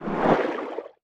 File:Sfx creature trivalve swim slow 04.ogg - Subnautica Wiki
Sfx_creature_trivalve_swim_slow_04.ogg